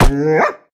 Minecraft Version Minecraft Version snapshot Latest Release | Latest Snapshot snapshot / assets / minecraft / sounds / mob / wolf / grumpy / hurt2.ogg Compare With Compare With Latest Release | Latest Snapshot
hurt2.ogg